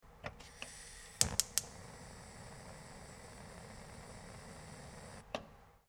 На этой странице собраны звуки работающей газовой колонки – от розжига до равномерного гудения.
Шум воспламенения газовой колонки